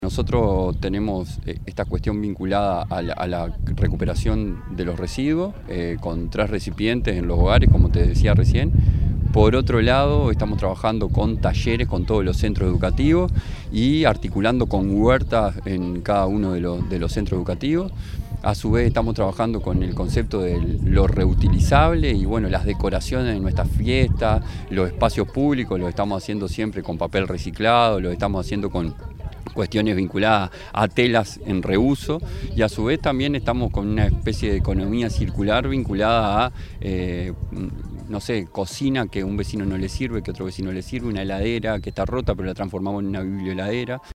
rodrigo_roncio_alcalde_los_cerrillos.mp3